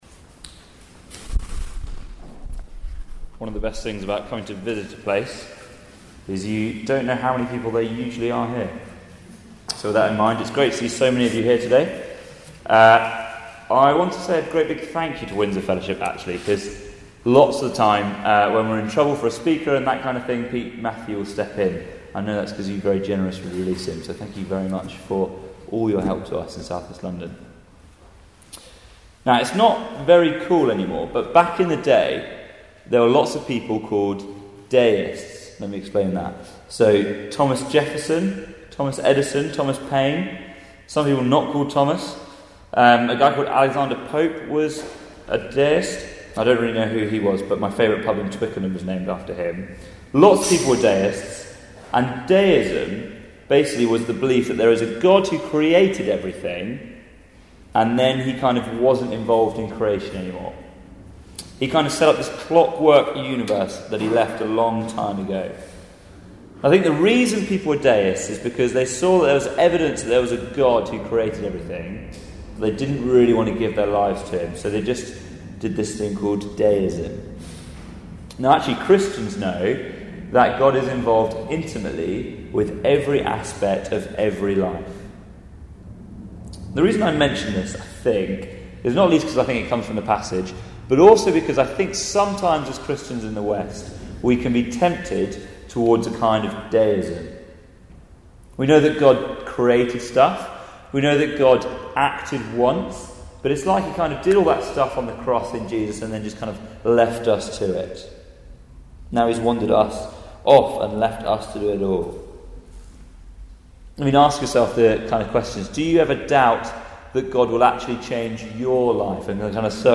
Philippians 4:2-9 Service Type: Weekly Service at 4pm Bible Text